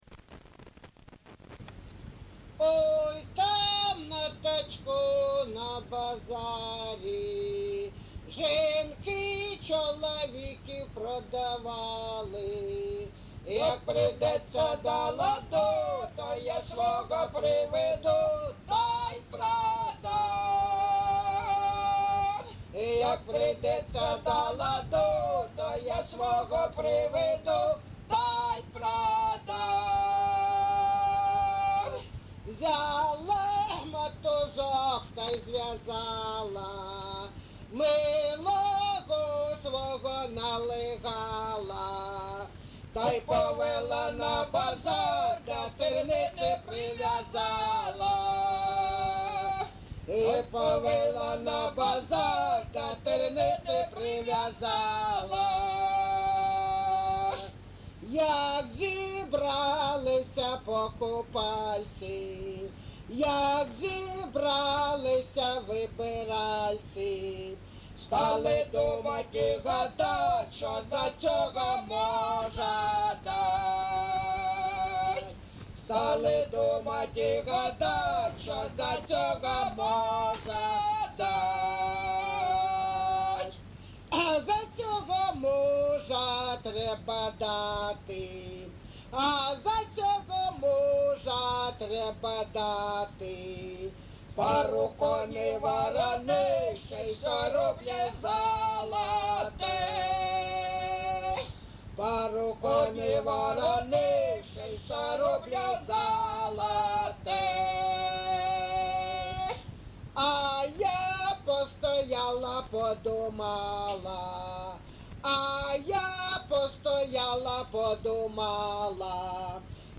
ЖанрЖартівливі
Місце записус. Григорівка, Артемівський (Бахмутський) район, Донецька обл., Україна, Слобожанщина